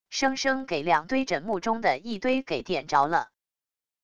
生生给两堆枕木中的一堆给点着了wav音频生成系统WAV Audio Player